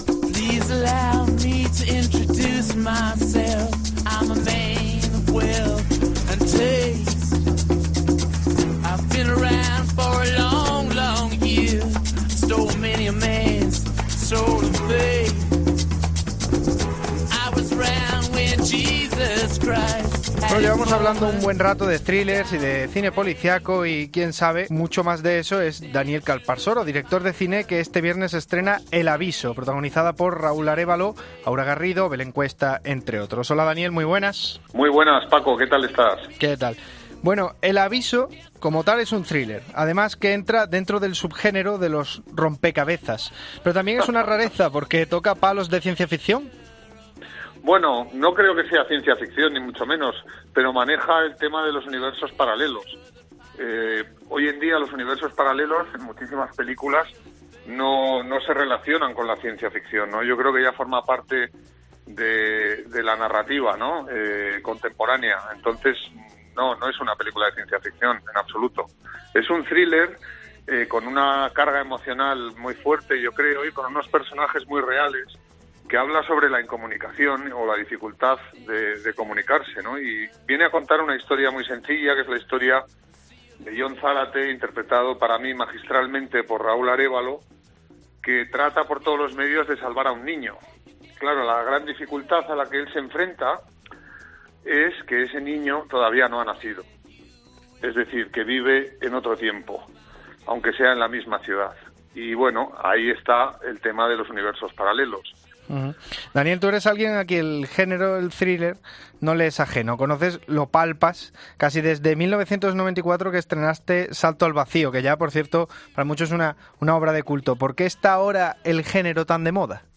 Entrevista a Daniel Calparsoro sobre los thrillers españoles